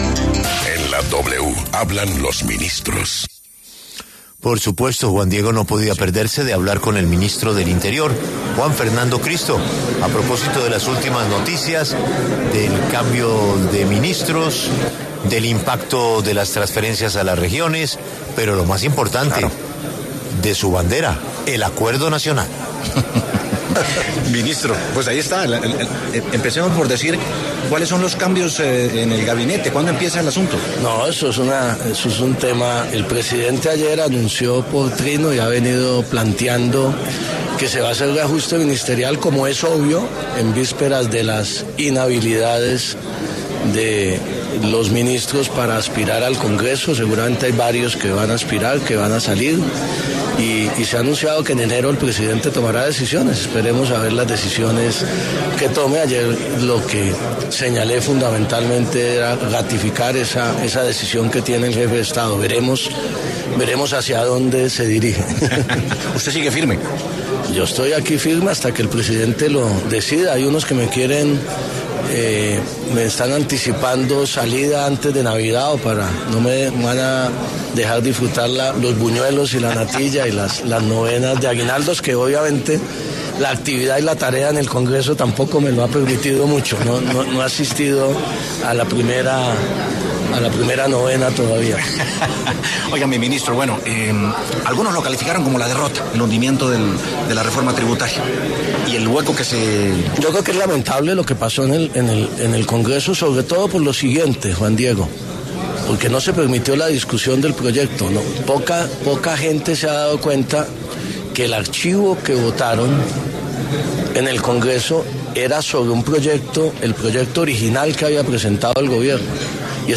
El ministro del Interior, Juan Fernando Cristo, pasó por los micrófonos de La W con Julio Sánchez Cristo y entregó detalles sobre los próximos cambios que se harán en el gabinete presidencial.